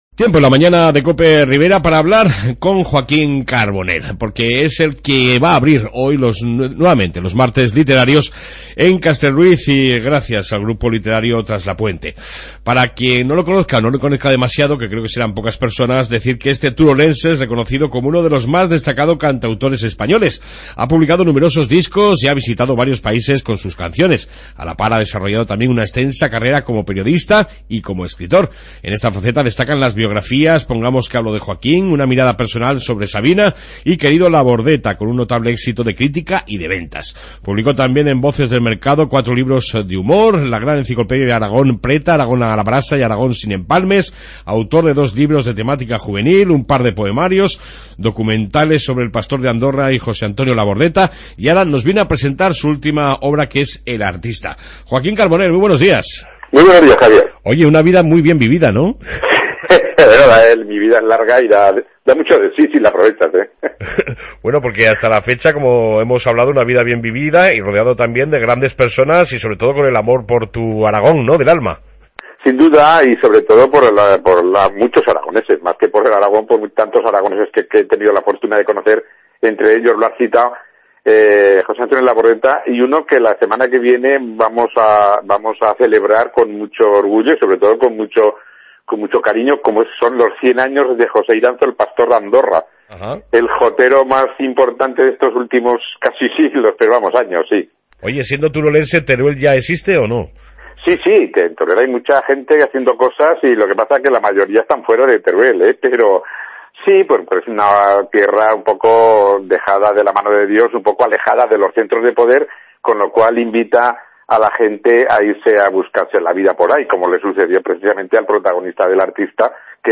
AUDIO: Y hoy hemos mantenido una entrañable entrevista con el escritor, Cantautor y periodista JOAQUÍN CARBONELL. que ha inaugurado los Martes...